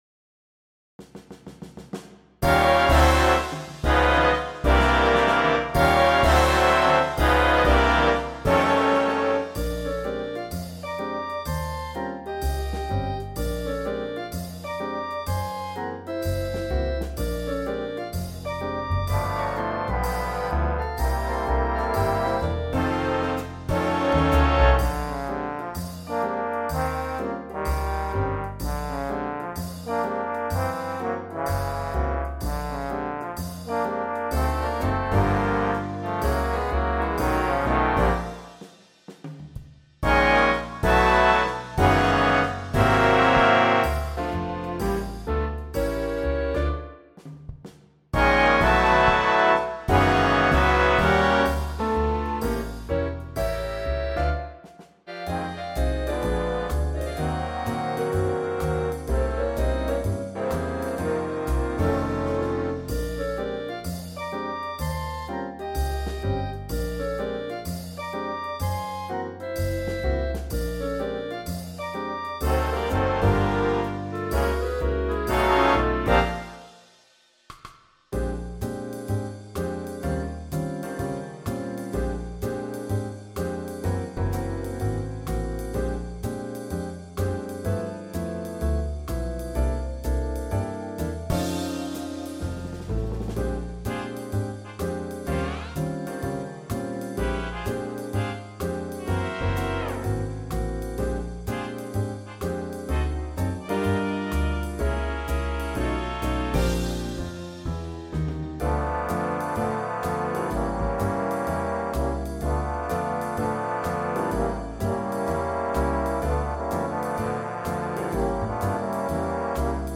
Medium Swing